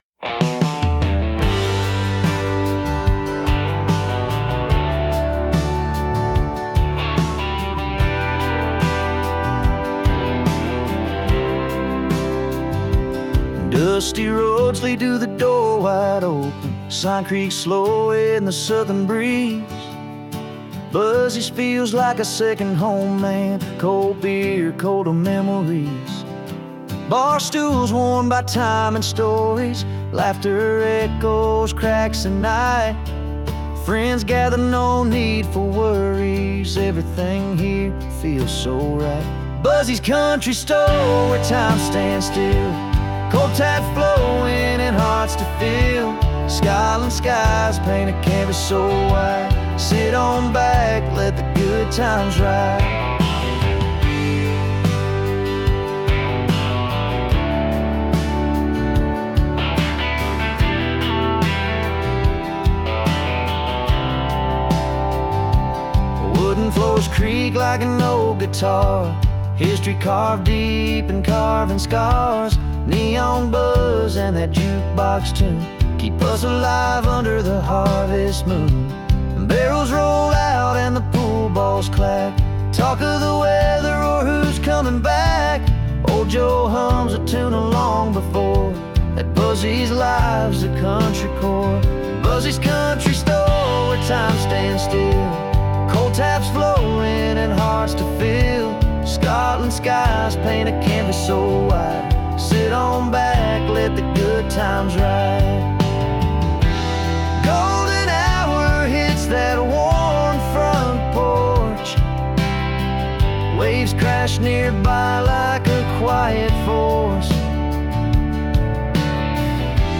AI wizardry